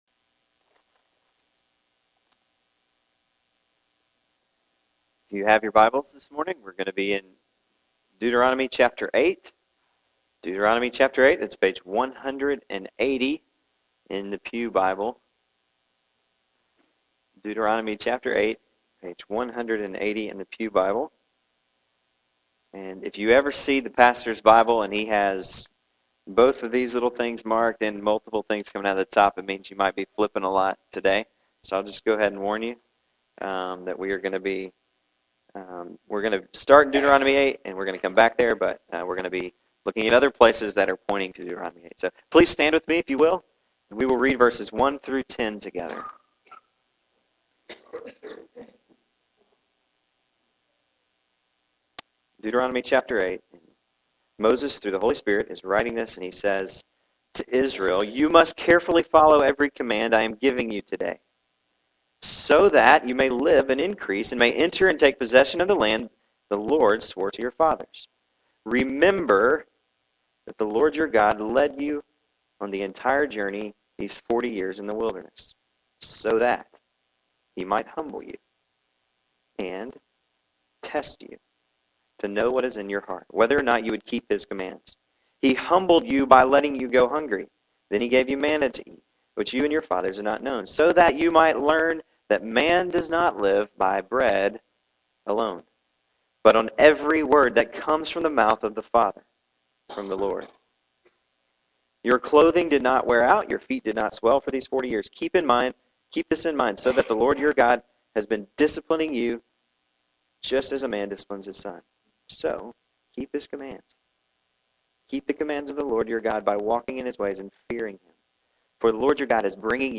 Here are the sermon audio files for messages presented in October, 2010 at Vine Street Baptist Church.